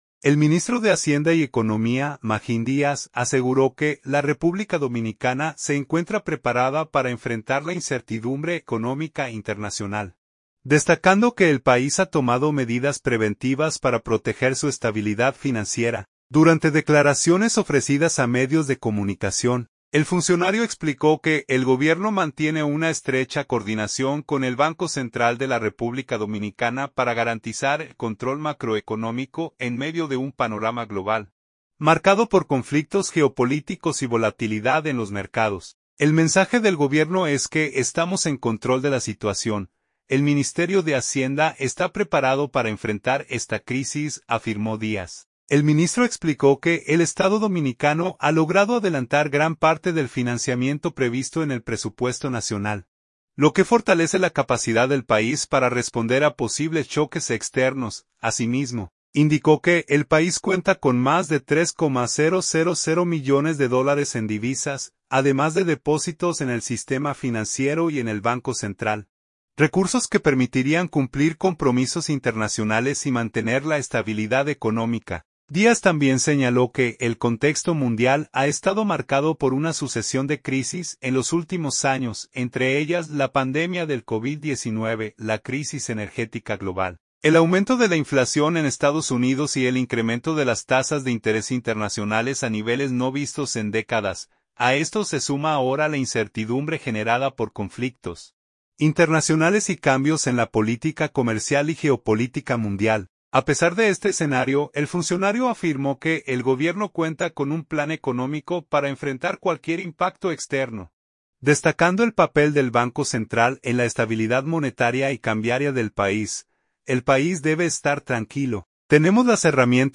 Durante declaraciones ofrecidas a medios de comunicación, el funcionario explicó que el gobierno mantiene una estrecha coordinación con el Banco Central de la República Dominicana para garantizar el control macroeconómico en medio de un panorama global marcado por conflictos geopolíticos y volatilidad en los mercados.